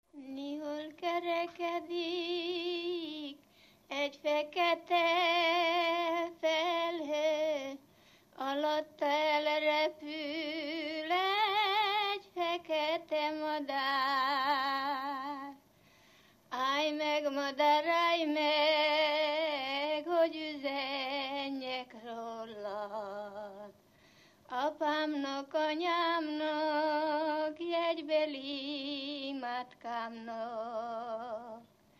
Erdély - Kolozs vm. - Visa
ének
Stílus: 3. Pszalmodizáló stílusú dallamok